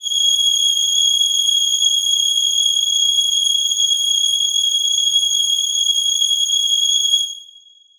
Choir Piano (Wav)
G#7.wav